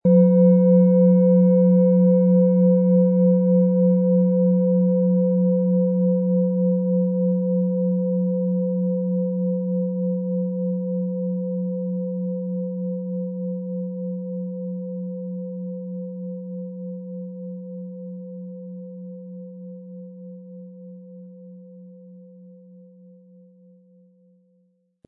Handgetriebene, tibetanische Planetenklangschale Wasser.
• Mittlerer Ton: Biorhythmus Geist
Um den Originalton der Schale anzuhören, gehen Sie bitte zu unserer Klangaufnahme unter dem Produktbild.
PlanetentöneWasser & Biorhythmus Geist
MaterialBronze